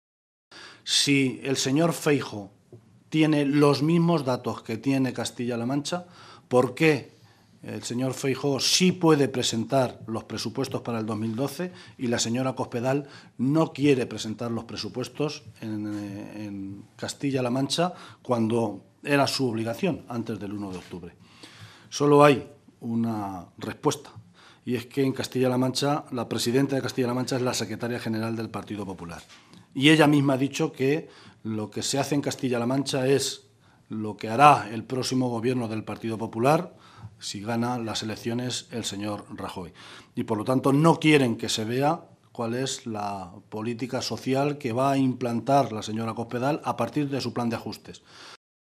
Cortes de audio de la rueda de prensa